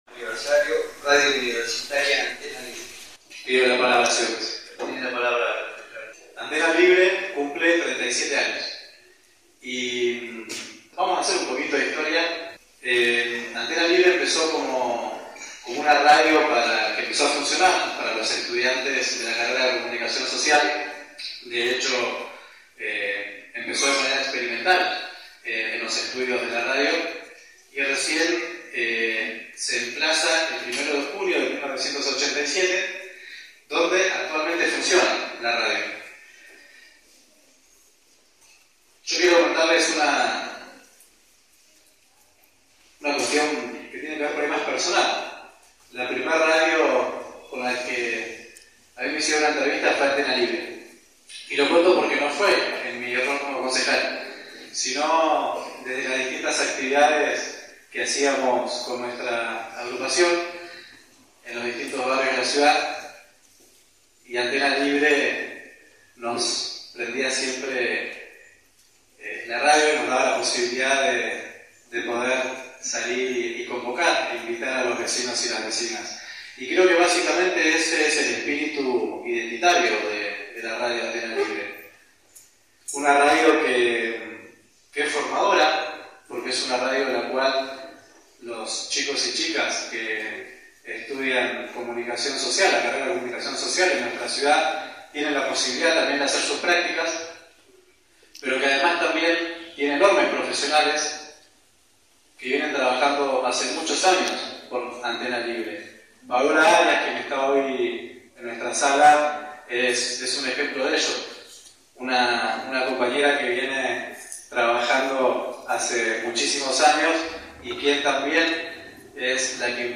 Desde «El Hilo Invisible» estuvimos presentes en el Concejo Deliberante de nuestra ciudad, donde se decidió en sesión declarar de Interés Municipal las actividades que se estarán realizando en marco del Aniversario N°37 de nuestra Radio, Antena Libre
Escuchamos la intervención del Concejal Nicolás Pascheta, seguido de las declaraciones del Concejal Juan Mercado y Concejala Nadia Ortiz: